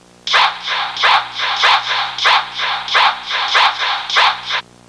Chanting Intro Sound Effect
Download a high-quality chanting intro sound effect.
chanting-intro.mp3